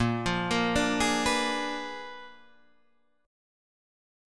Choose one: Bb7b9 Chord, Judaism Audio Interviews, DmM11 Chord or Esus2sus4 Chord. Bb7b9 Chord